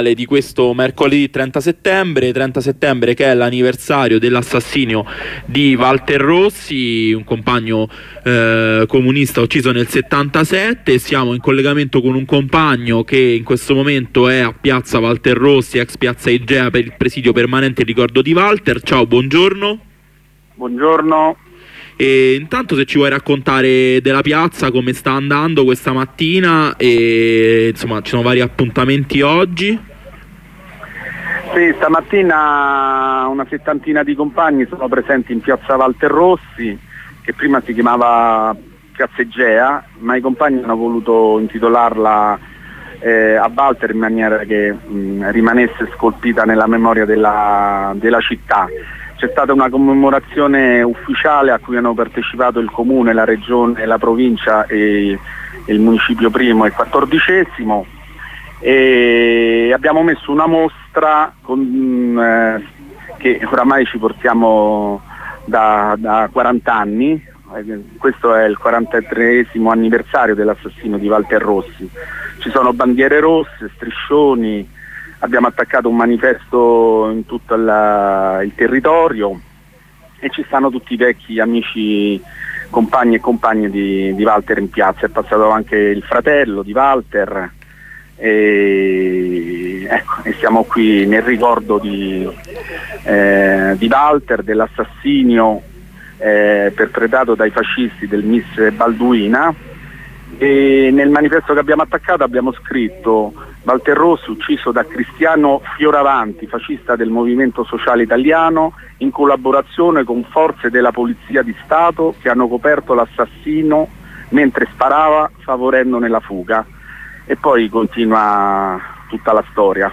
Corrispondenza con un compagno dalla piazza
Nella corrispondenza la voce di un compagno dalla piazza.